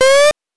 8 bits Elements
jump_6.wav